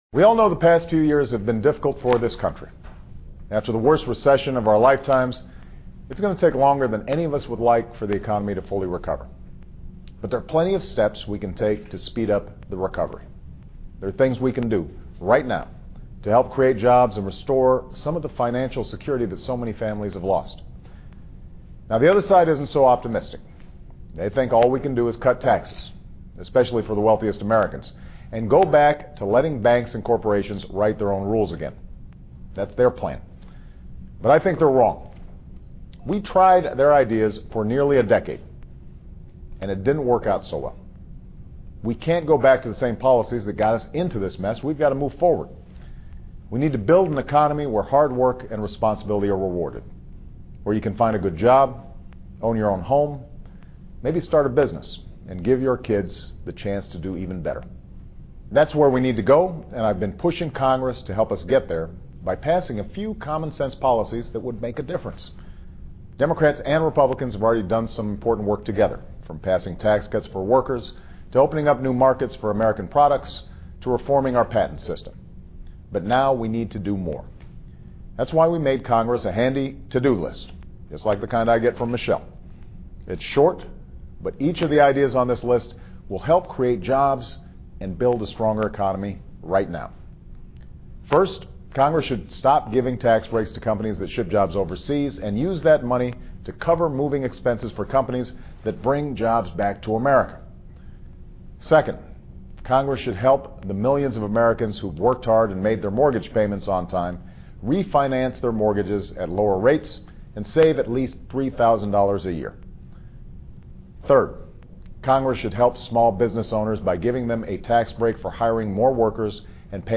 奥巴马总统每周电台演讲:总统呼吁国会逐一完成各个待办事项 听力文件下载—在线英语听力室